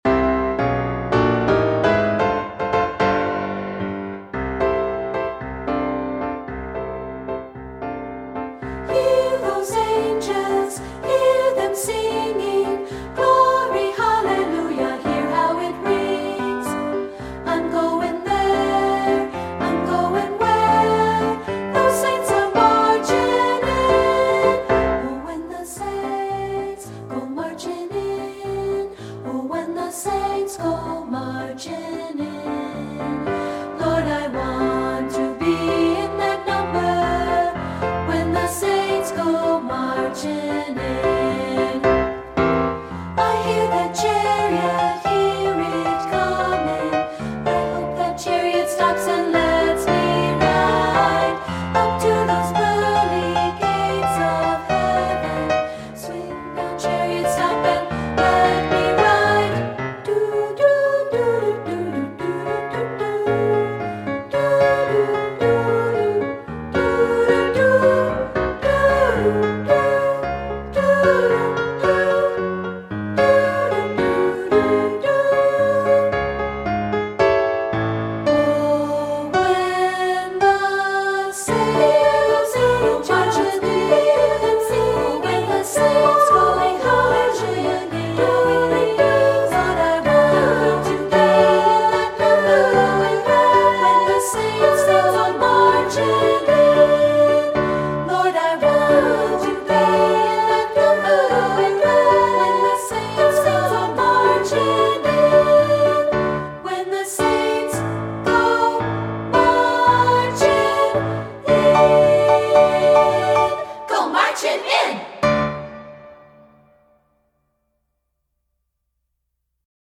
Voicing: 2-Part